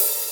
hihat-open.wav